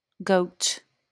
amer. IPA/ɡoʊt/, X-SAMPA: /goUt/
wymowa amerykańska?/i